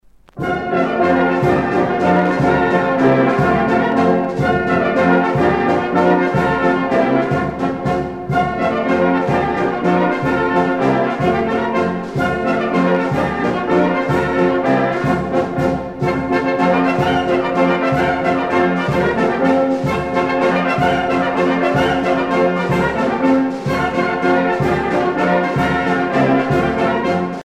danse : ruchenitza (Bulgarie)
Pièce musicale éditée